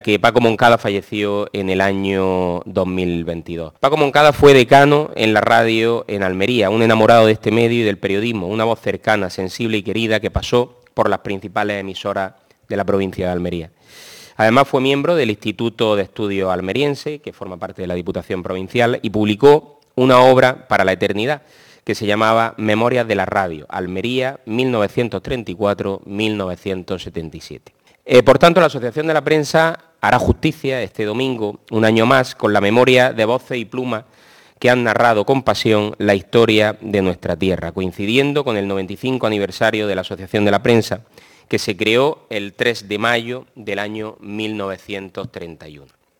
Esta mañana, en el Palacio Provincial, el vicepresidente Álvaro Izquierdo
27-04_prensa_diputado.mp3.mp3